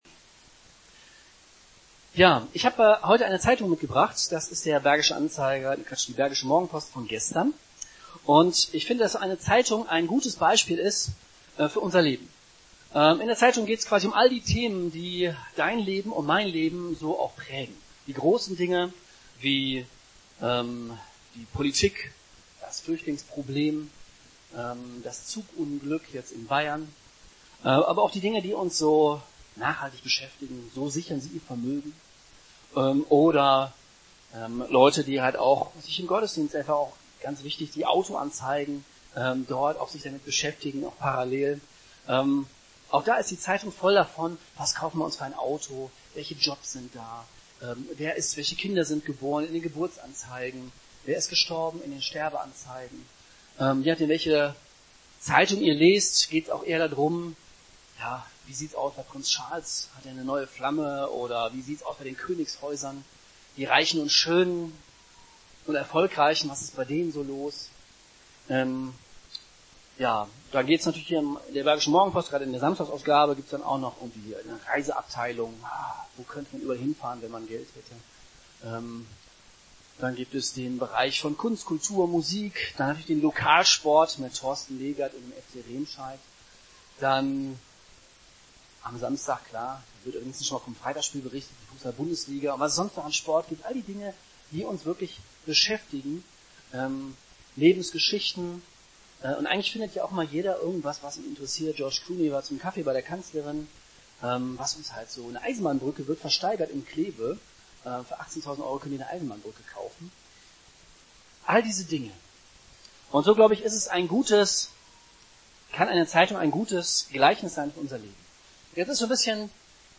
Predigten der Jesus Freaks Remscheid